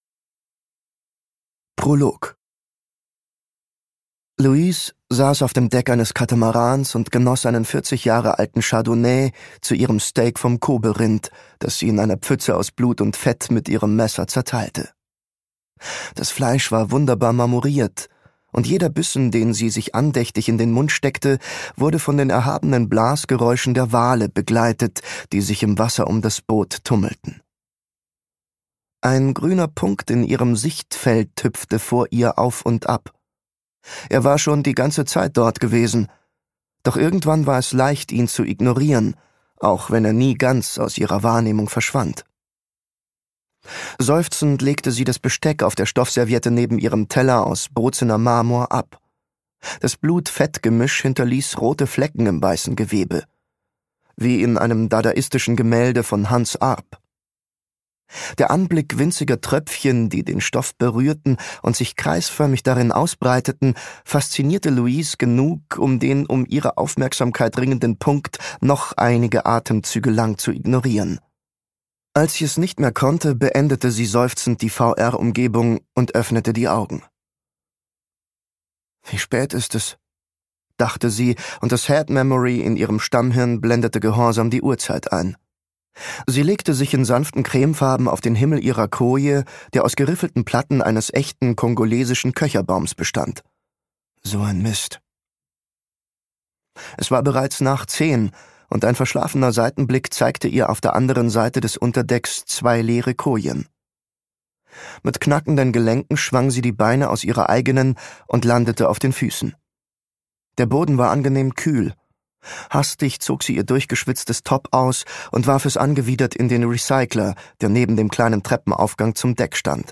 Singularity - Joshua Tree | argon hörbuch
Gekürzt Autorisierte, d.h. von Autor:innen und / oder Verlagen freigegebene, bearbeitete Fassung.